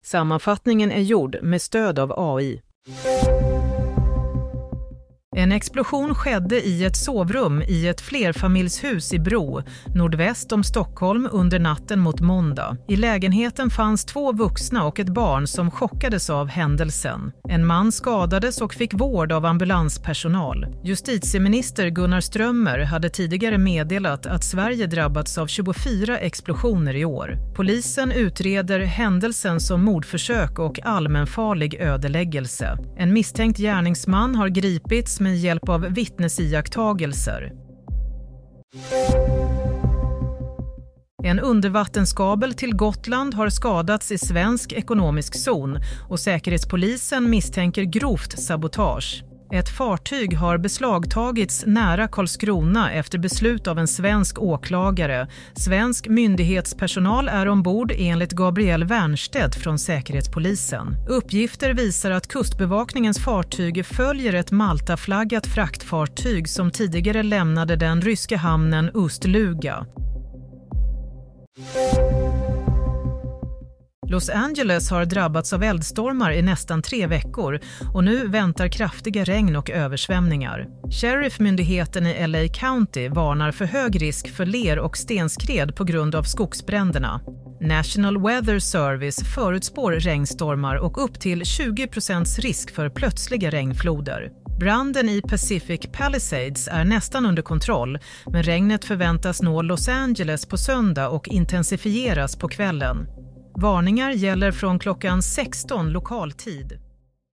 Nyhetssammanfattning - 27 januari 07.00